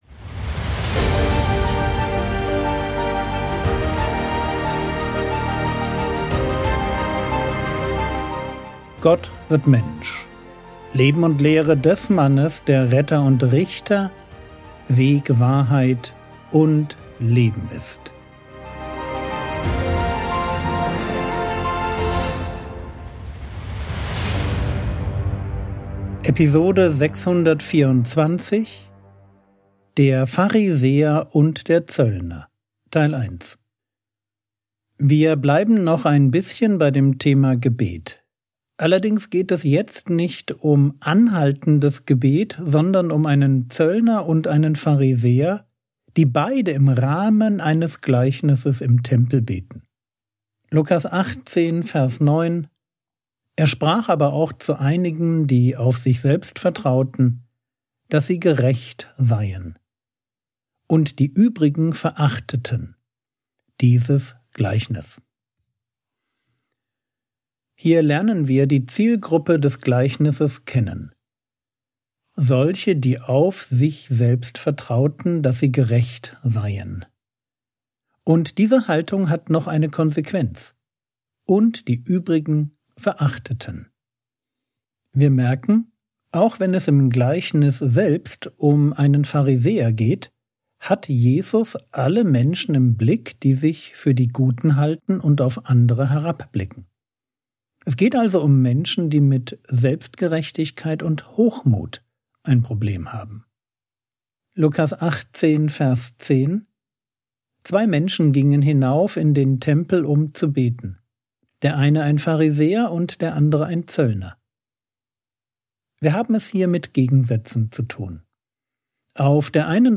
Episode 624 | Jesu Leben und Lehre ~ Frogwords Mini-Predigt Podcast